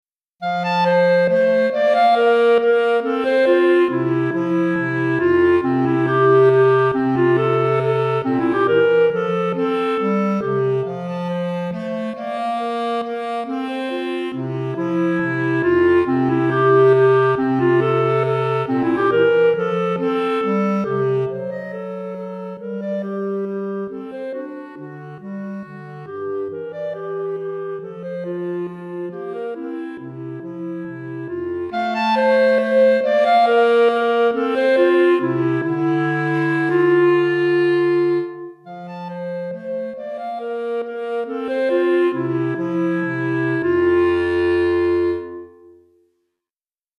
Clarinette en Sib et Clarinette Basse